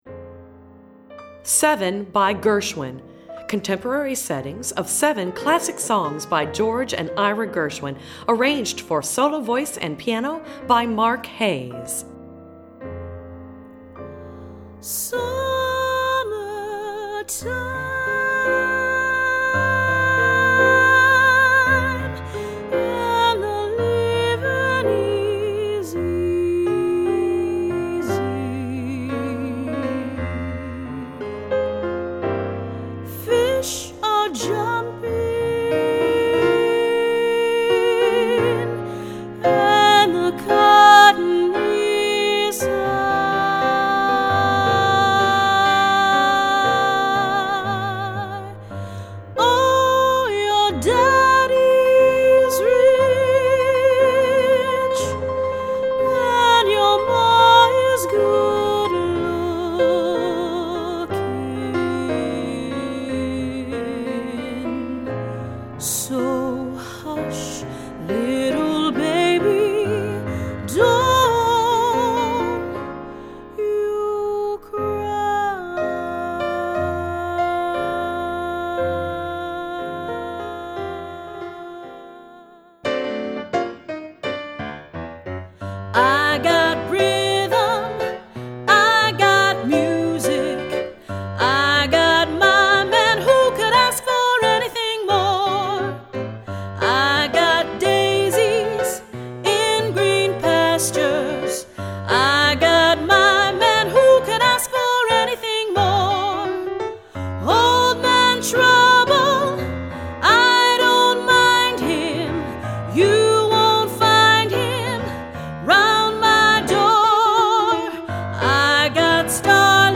Voicing: Medium-High Voice